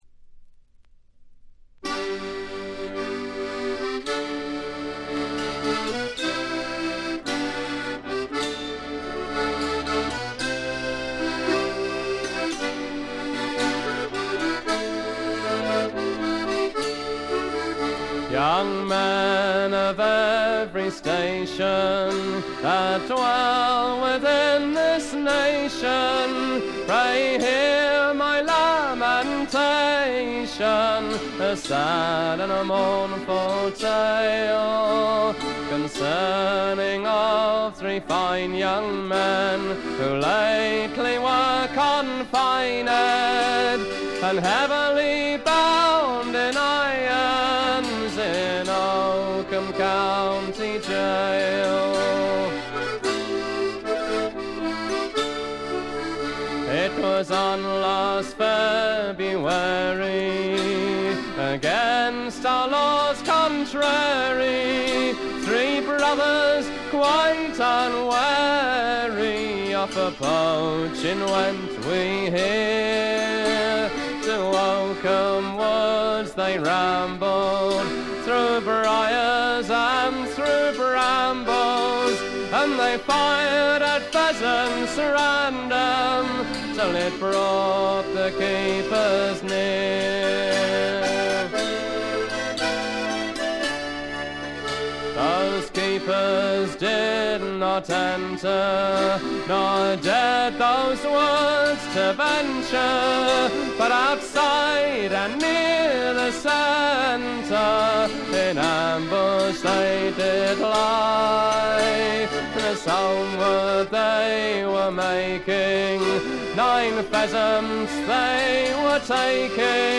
部分試聴ですが、静音部で軽微なチリプチが少し、散発的なプツ音が少し。
本作もトラッド・アルバムとして素晴らしい出来栄えです。
試聴曲は現品からの取り込み音源です。
Recorded At - Riverside Studios, London